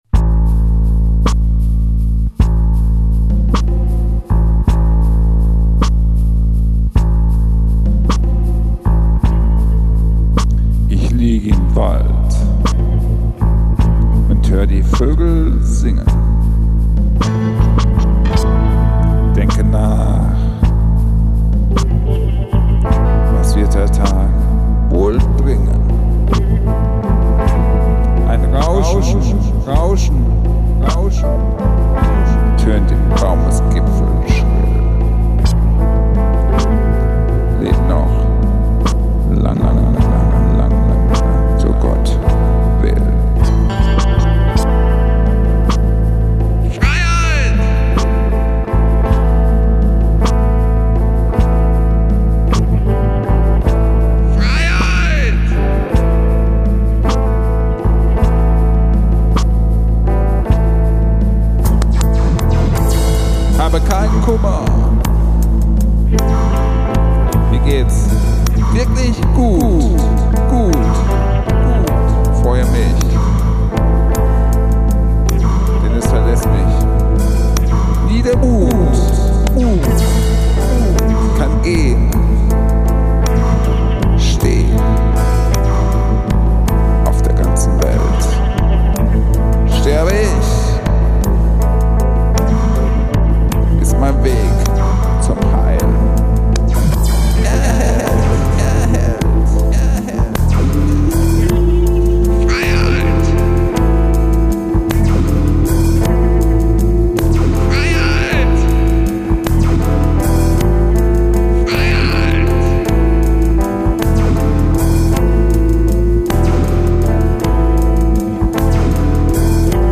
Ursprünglich als langsames Rockstück eingespielt, wurde es zu einer experimentellen Chillsession abgewandelt. Die Grundstruktur bleibt immer gleich, aber jeder Part hat sein eigenes Feeling.